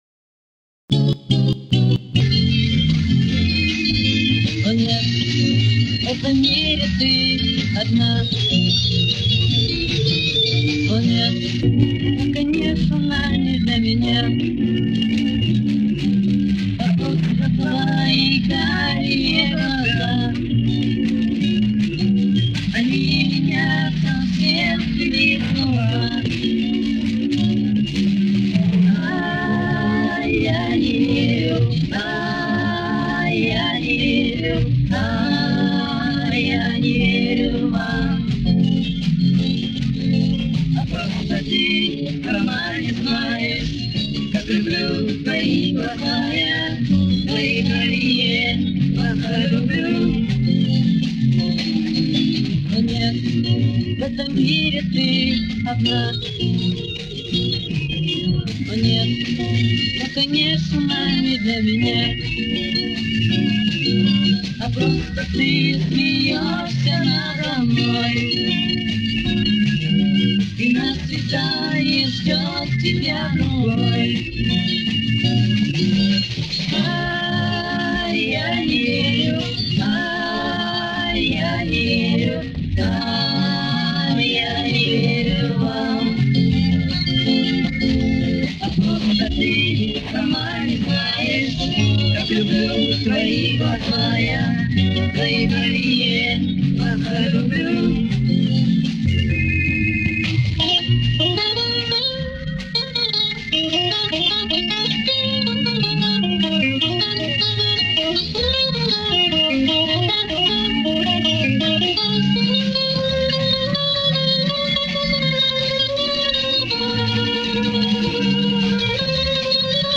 И, как обычно, в ужасном состоянии.